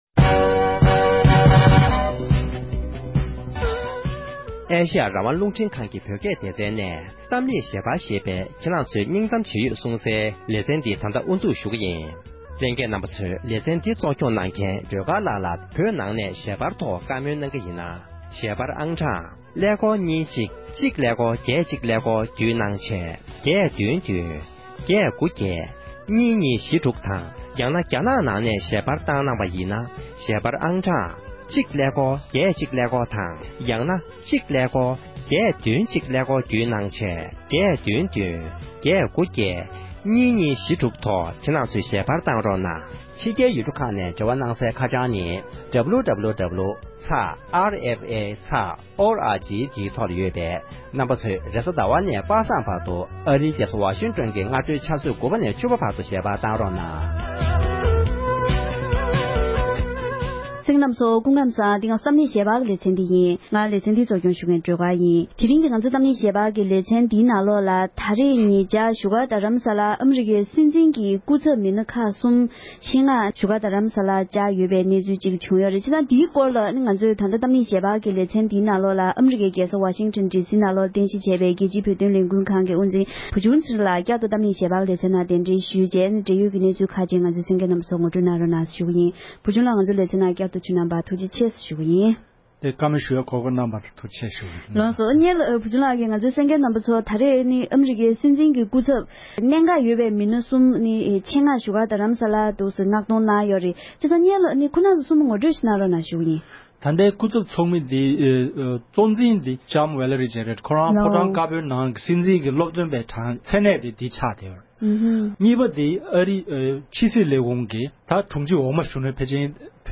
དེ་རིང་གི་གཏམ་གླེང་ཞལ་པར་གྱི་ལེ་ཚན་ནང་